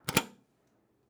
Switch (8).wav